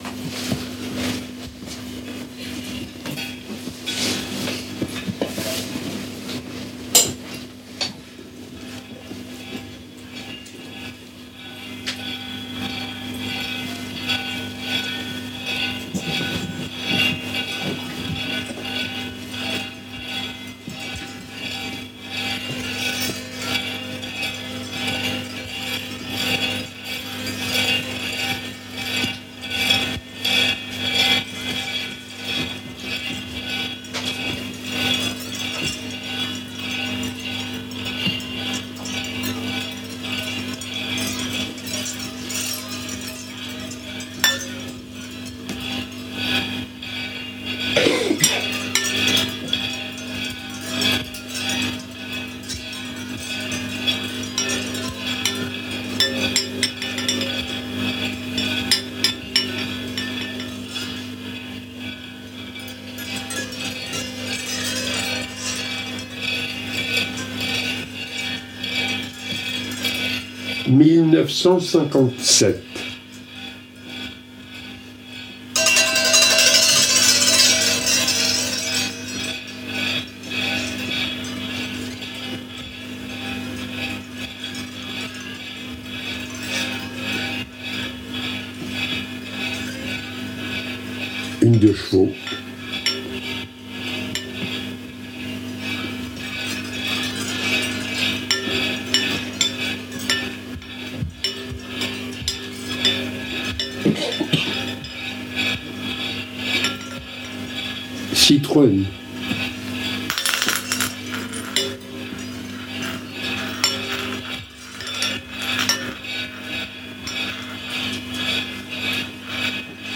guitare, ampli du moment, voix, harmonica
platines, objets sonores, phonographies, saxophone